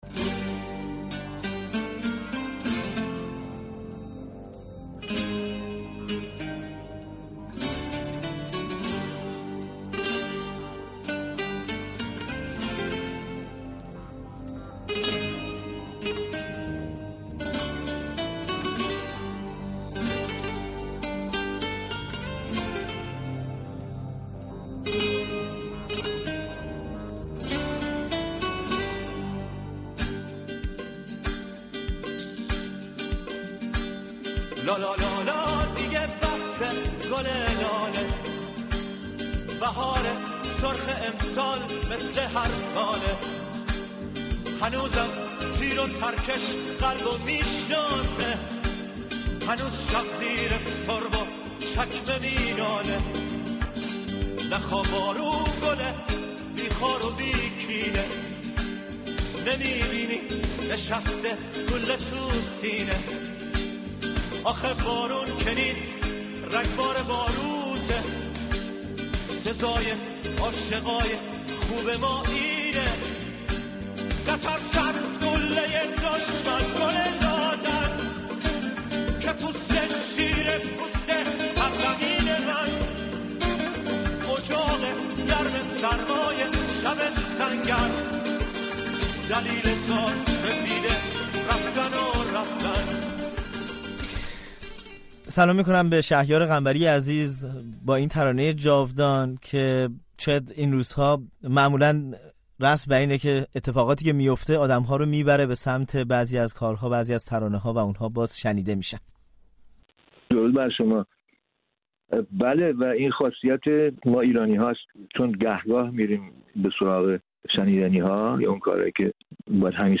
شهیار قنبری، ترانه‌سرای ایرانی، دو ترانهٔ شاخص ضد جنگ دارد، «لالا دیگه بسه» و «جنگجو». او در گفت‌وگو با رادیو فردا از تجربهٔ این دو ترانه و سایهٔ جنگ اسرائیل بر سر ایران گفته است.